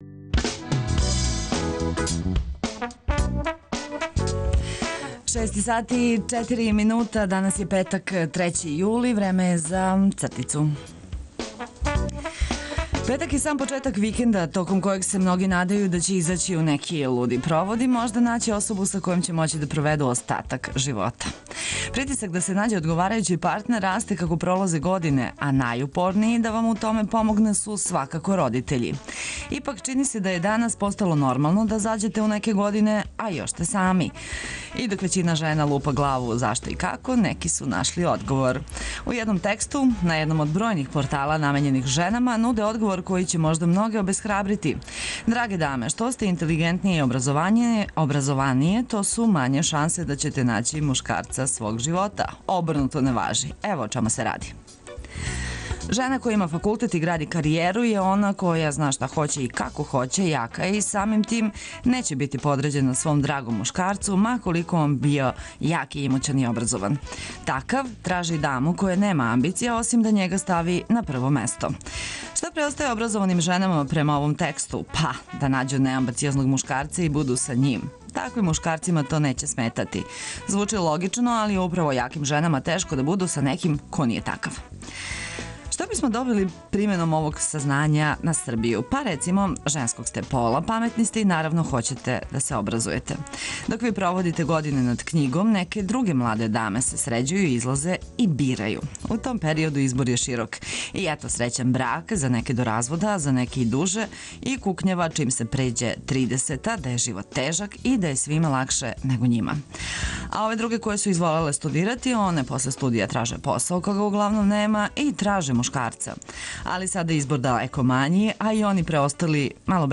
Јутарњи програм Београда 202.